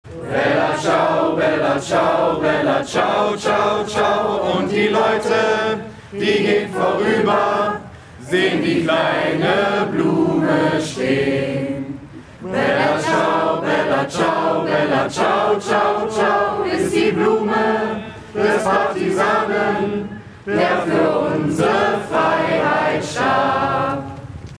bella-ciao-bei-der-mahnwache-vor-der-cdu.mp3